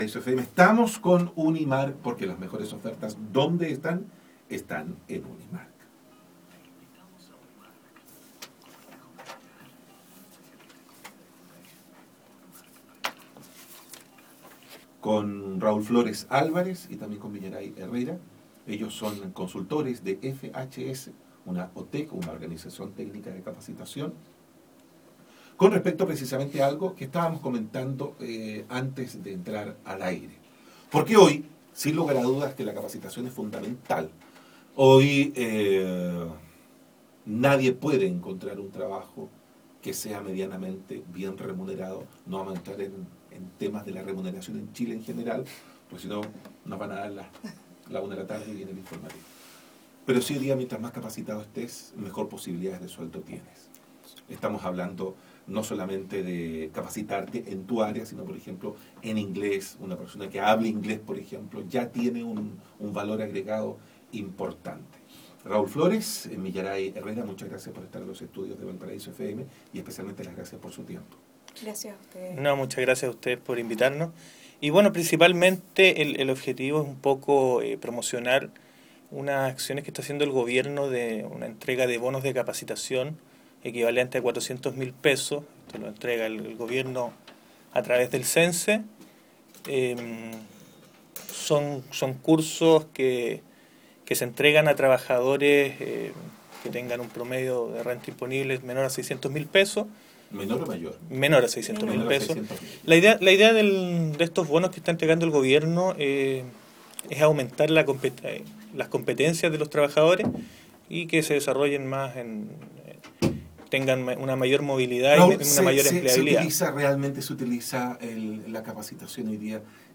Entrevista Bono Trabajador Activo - Radio Valparaiso
Entrevista_Radio_Valparaiso_-_copia.mp3